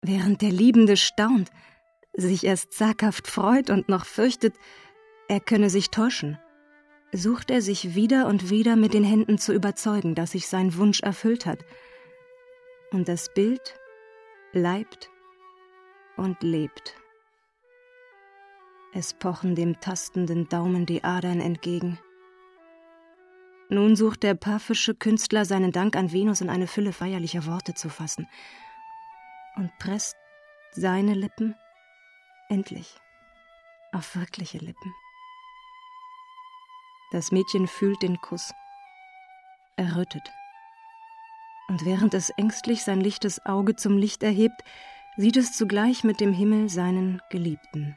Sprechprobe: eLearning (Muttersprache):
german female voice over artist